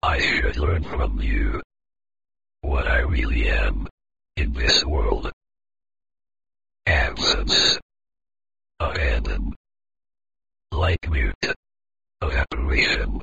Paradossalmente le poesie hanno una forte componente umana e la macchina le legge con una voce mal modulata, a tratti incerta, ma molto calda e profonda.
It's not a recorded sound reproduced from time to time, but the voice of the microprocessor that synthesizes words in real time.
Paradoxally, poems have a strong human component and the machine reads them with its voice, not well modulated, uncertain, but very warm and deep.
What impress and disturb more, is the sensation of primordiality that this "soft mechanical voice" is able to evoke.
Ascolta la registrazione delle poesie lette dal computer / Listen to the recording of the poems read by the computer